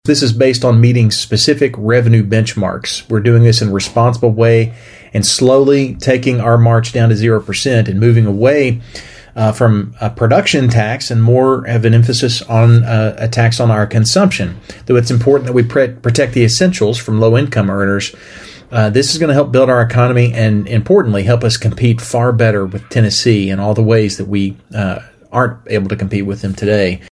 Third District State Senator Whitney Westerfield, who serves on behalf of Christian, Caldwell, and Muhlenburg counties, says the legislation passed because the state continues to meet the benchmarks required.